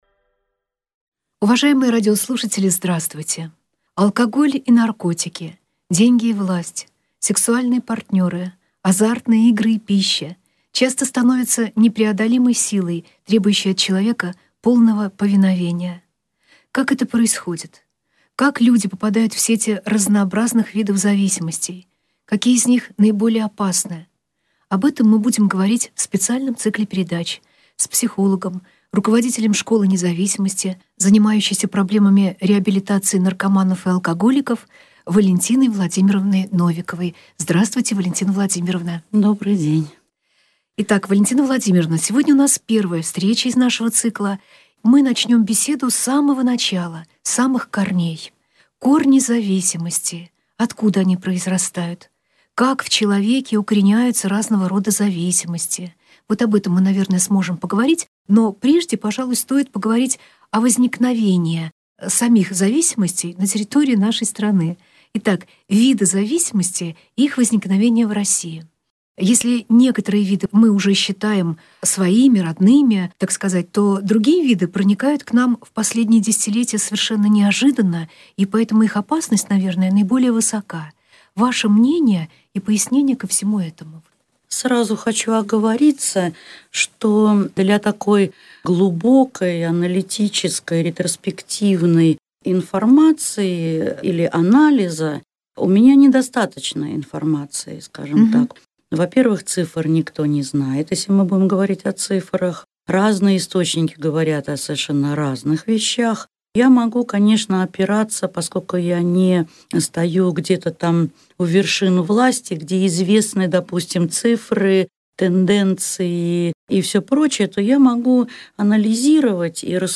«Выбираю свободу!» – аудиодиск о том, как избежать формирования зависимого поведения Беседы